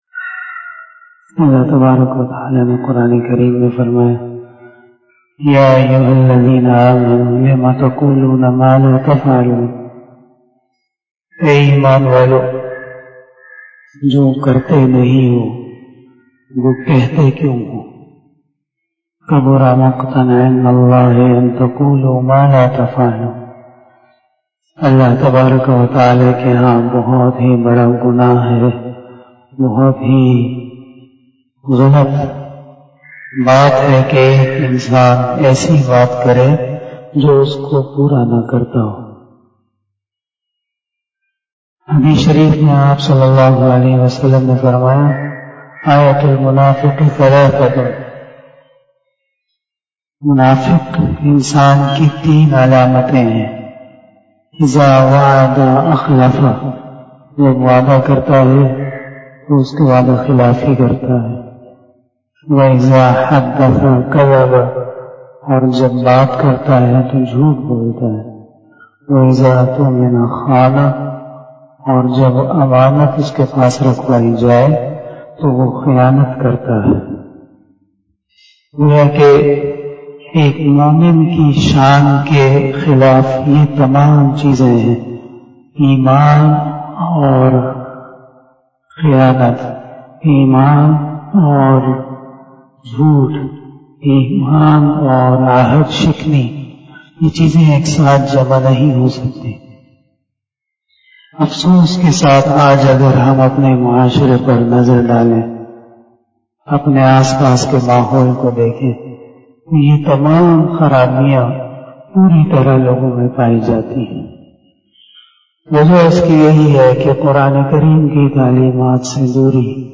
001 After Fajar Namaz Bayan 03 January 2021 ( 18 Jamadil Uola 1442HJ) Sunday